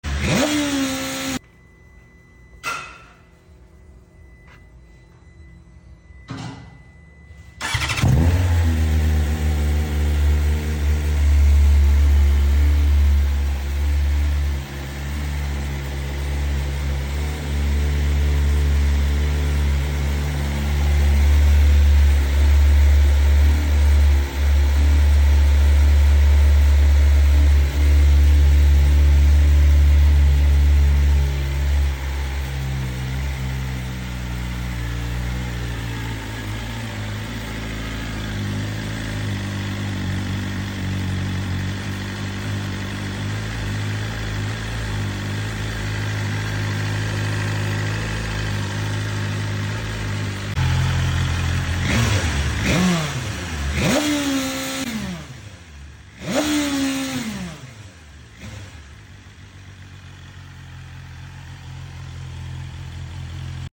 S1000rr bloqueada e escapamento original sound effects free download